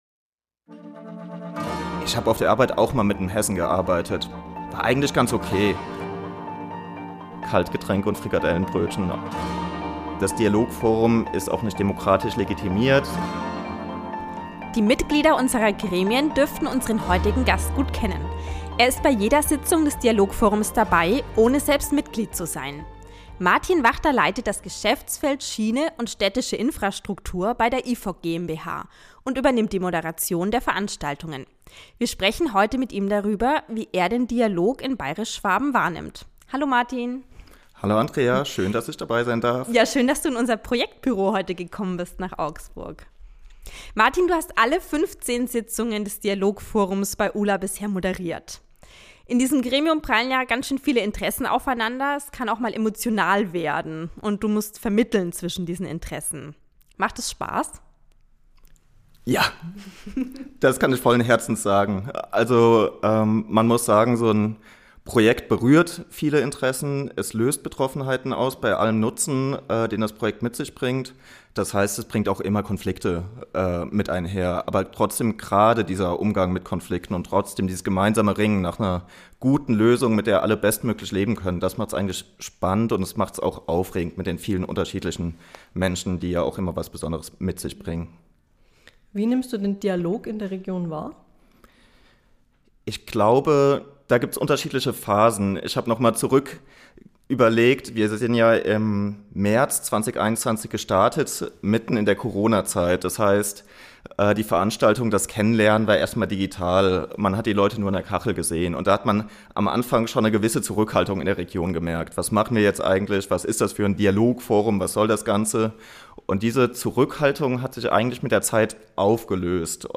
Ein Gespräch über erforderliche Opfer, Haltung und ob man sich das Thema Öffentlichkeitsarbeit nicht sparen könnte.